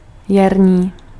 Ääntäminen
Synonyymit gai Ääntäminen France: IPA: [pʁɛ̃.ta.nje] Haettu sana löytyi näillä lähdekielillä: ranska Käännös Ääninäyte Adjektiivit 1. jarní Suku: m .